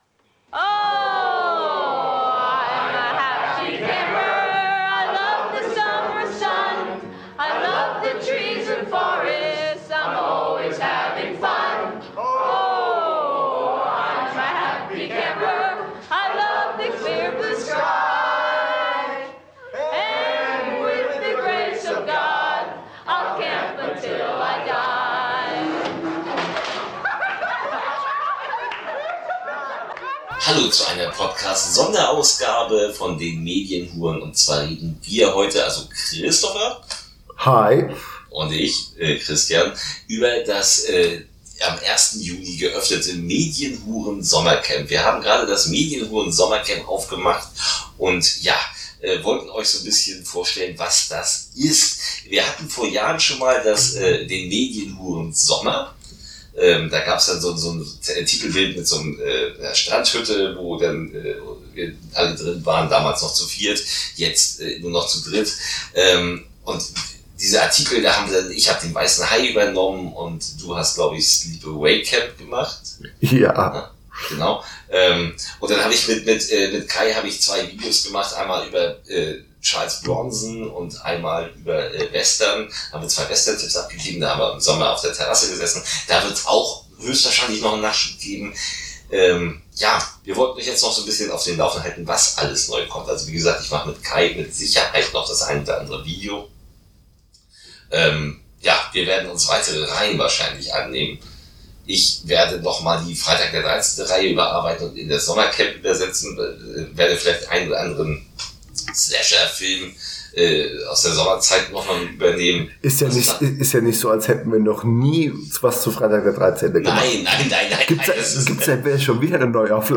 Zombies, Bücher und ganz viel Off-Topic: Interview